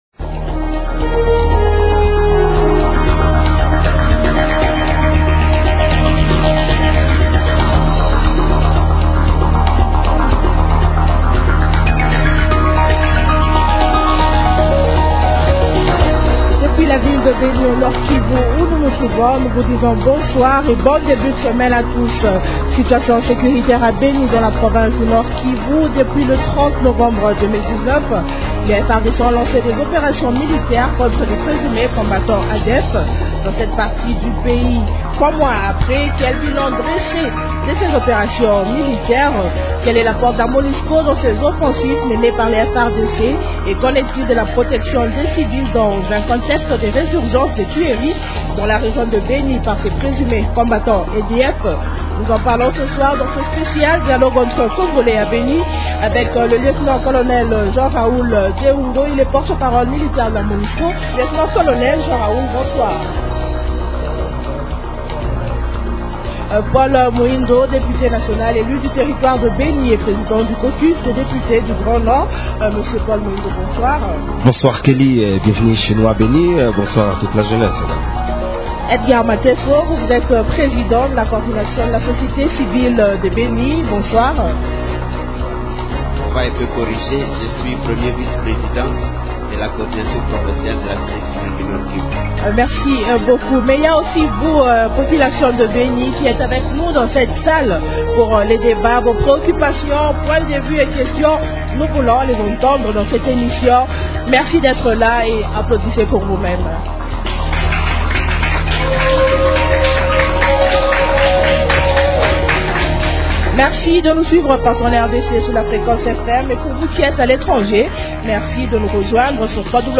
Emission spéciale Dialogue Entre Congolais réalisée à Beni au Nord-Kivu sur les opérations militaires en cours contre les rebelles des ADF. Trois mois après leur lancement, quelle évaluation faire de ces opérations ?